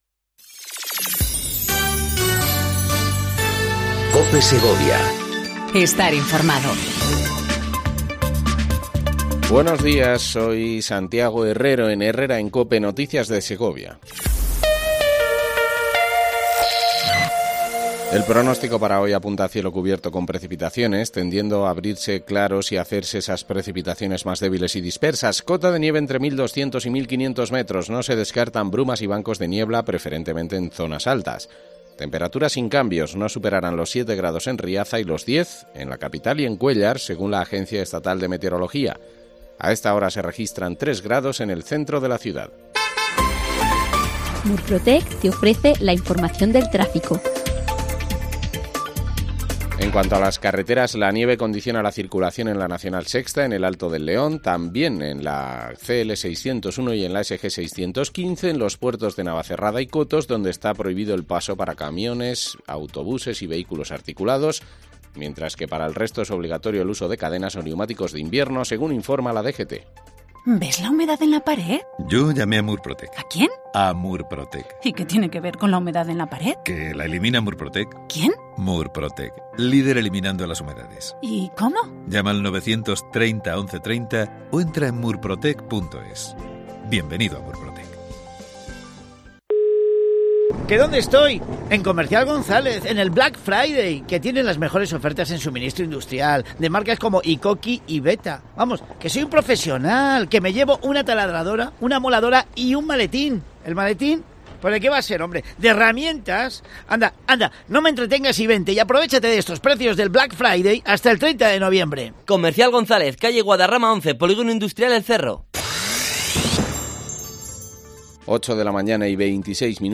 AUDIO: Segundo informativo local en cope segovia
INFORMATIVO LOCAL